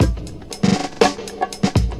• 2. リトリガー・ノート[Ex]・・・
サンプルをトリガーし直すコマンド。
lesson6-beat-02.mp3